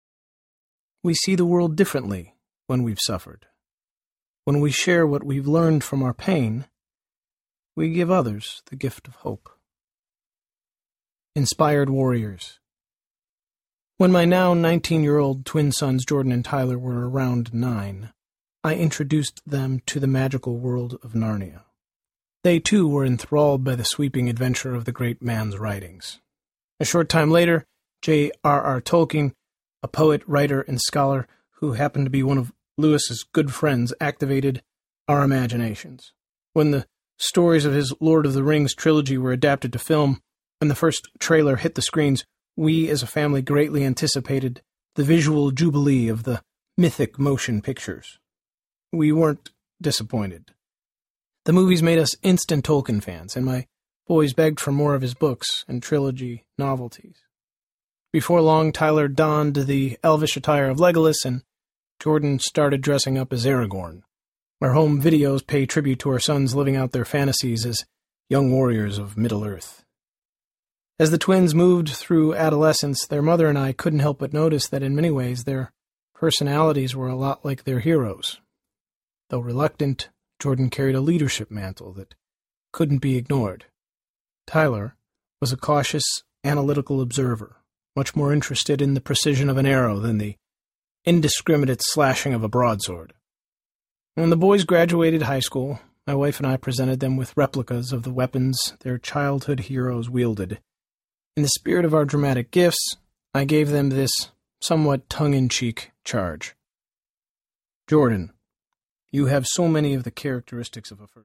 Poets and Saints Audiobook
Narrator
5.35 Hrs. – Unabridged